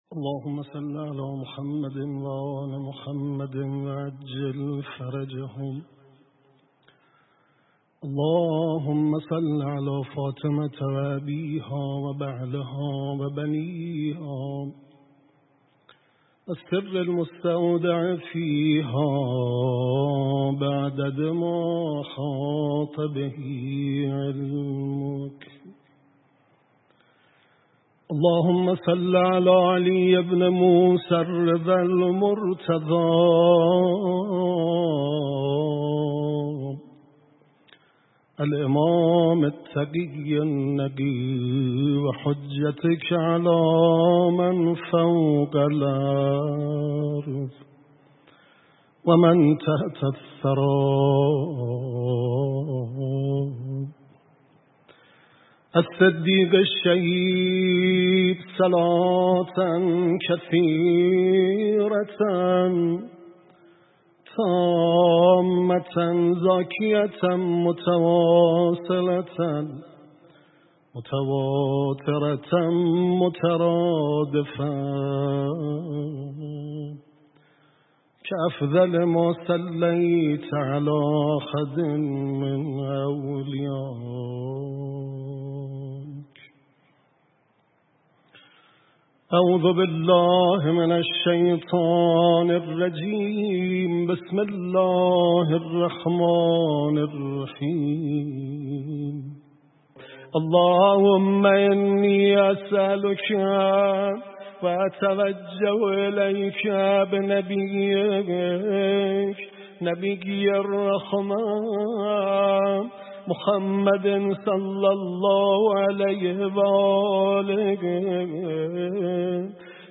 در اولین شب عزاداری ایام شهادت حضرت زهرا سلام الله علیها که با حضور رهبر معظم انقلاب اسلامی در حسینیه امام خمینی رحمه الله برگزار شد.